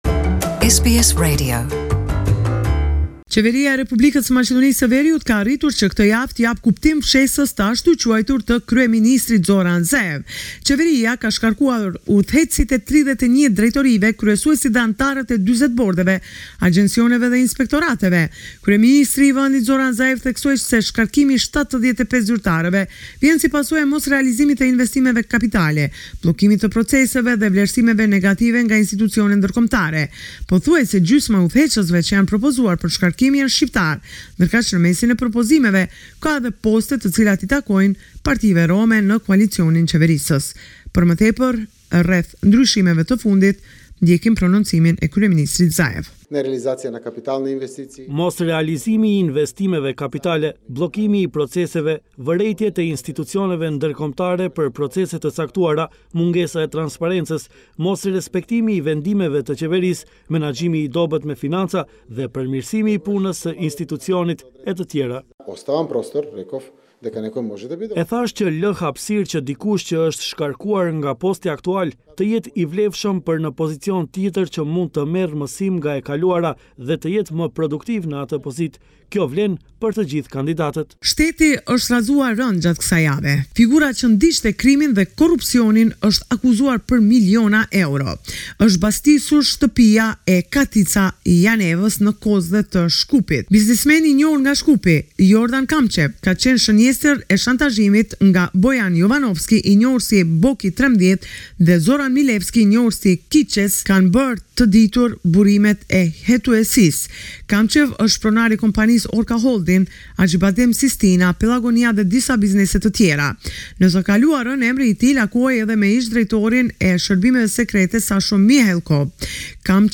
This is a report summarising the latest developments in news and current affairs in North Macedonia